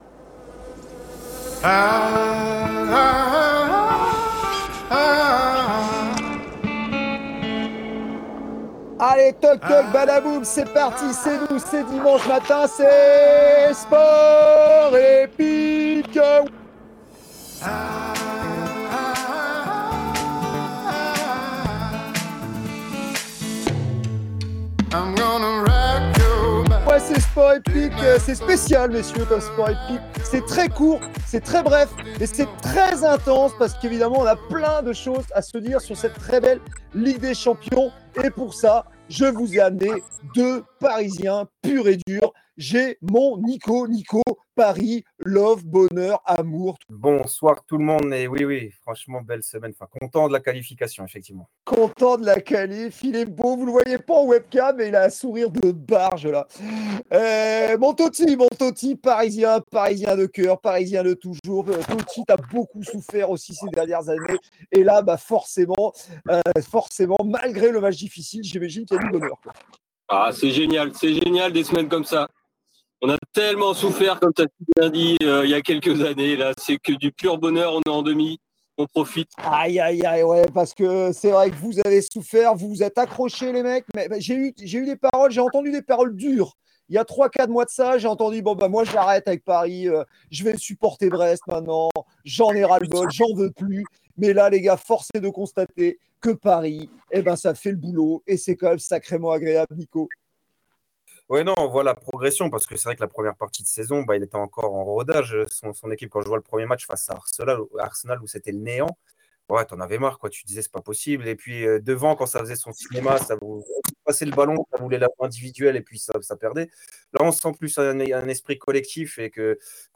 Interviews, quizz, chroniques, débats et bonne humeur seront au rendez-vous!